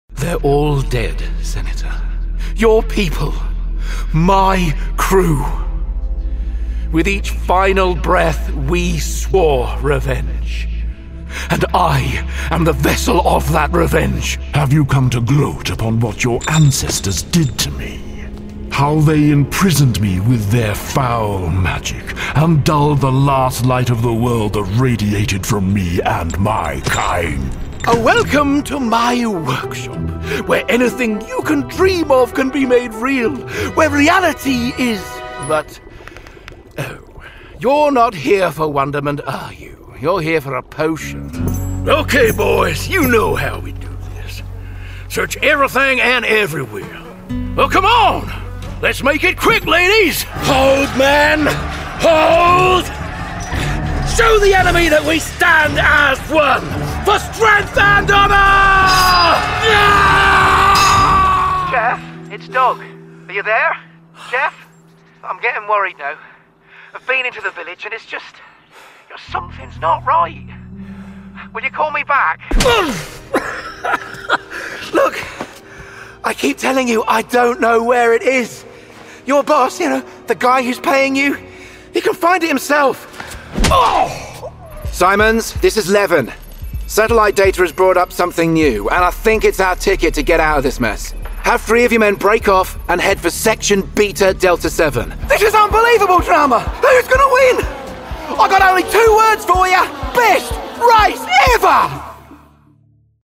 Video Games
Session Booth / Neumann TLM103 / Audient iD4 / MacBook Pro / Adobe Audition
BaritoneBassDeepLow
AnnouncerExpertFatherProfessorStorytellerWise Old ManWizard